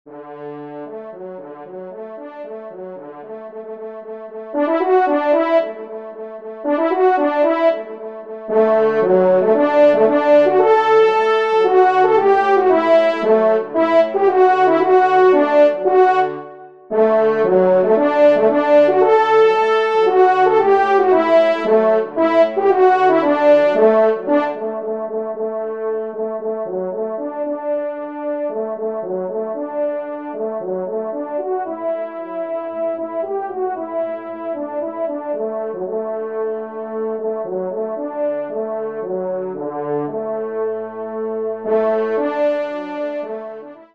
Genre :  Divertissement pour Trompes ou Cors en Ré
1e Trompe